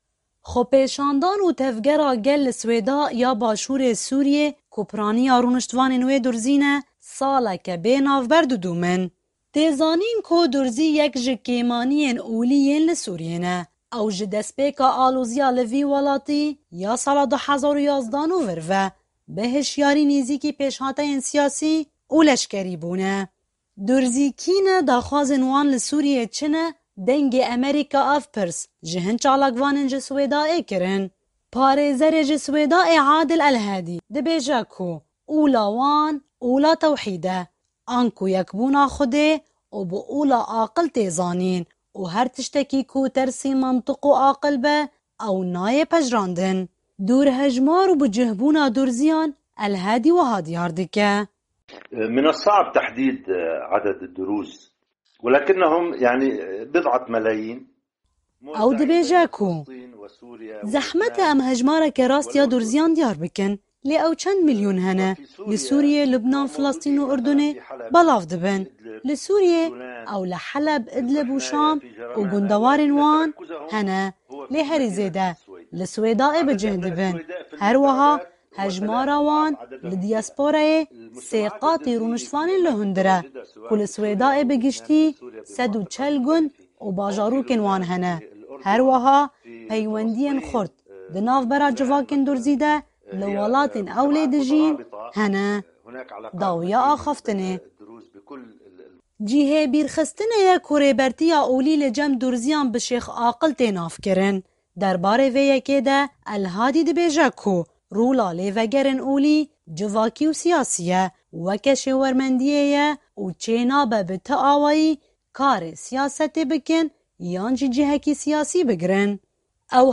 Durzî kî ne, daxwazên wan li Sûrîyê çi ne? Dengê Amerîka ev pirs ji hin çalakvanên ji Suweyda kirin.